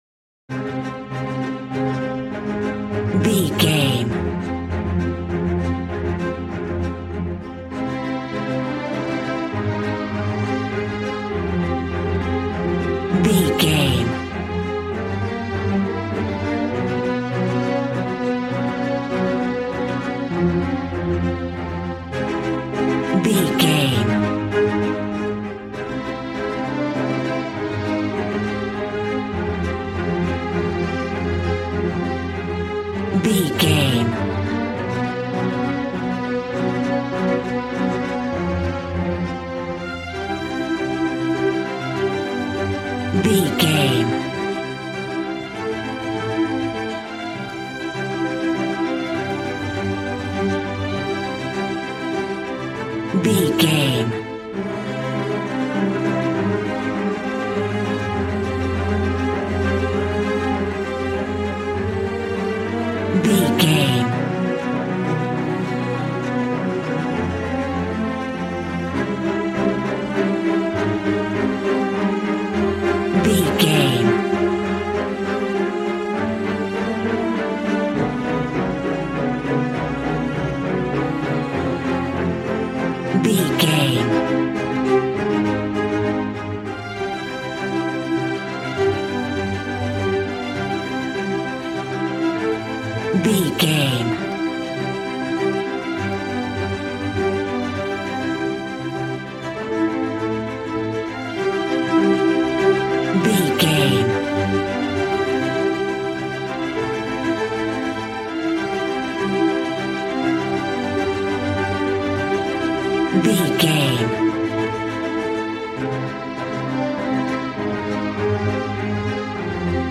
Regal and romantic, a classy piece of classical music.
Ionian/Major
regal
strings
brass